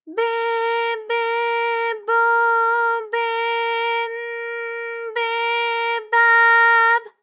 ALYS-DB-001-JPN - First Japanese UTAU vocal library of ALYS.
be_be_bo_be_n_be_ba_b.wav